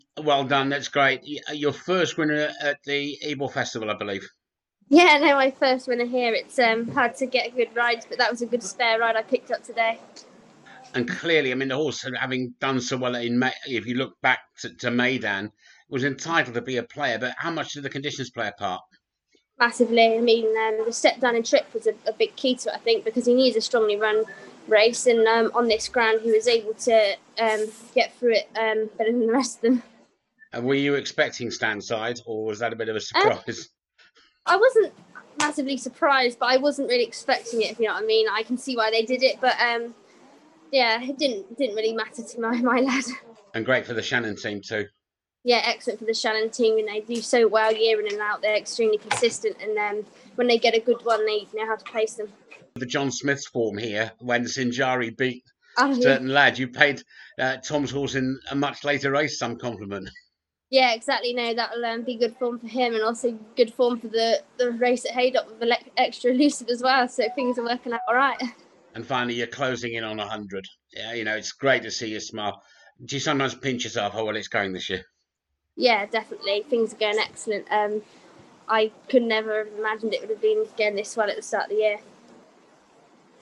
Jockey Hollie Doyle after Certain Lad landed the Group 3 Sky Bet and Symphony Group Stresall- her first winner at the Welcome to Yorkshire Ebor Festival.